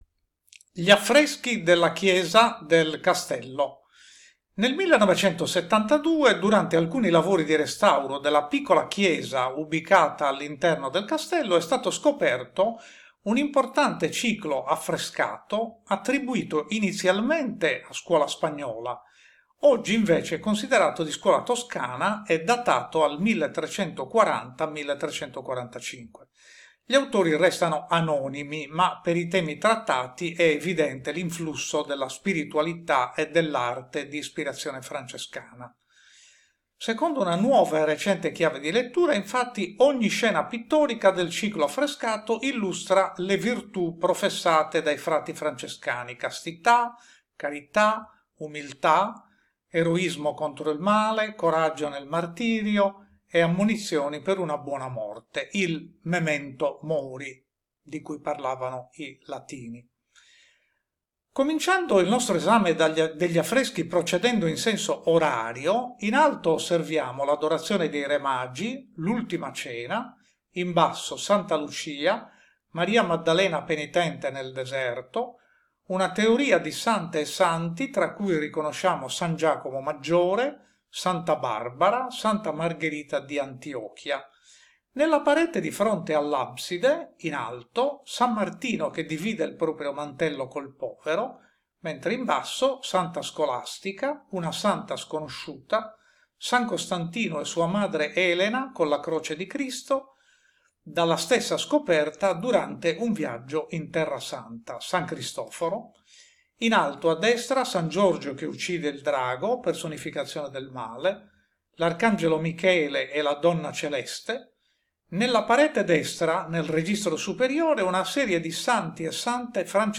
Audioguide - Audioguides